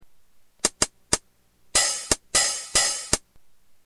JOMOX XBase09SE Hi Hat
Category: Sound FX   Right: Personal